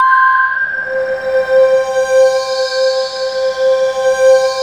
Index of /90_sSampleCDs/USB Soundscan vol.13 - Ethereal Atmosphere [AKAI] 1CD/Partition D/04-ACTUALSYN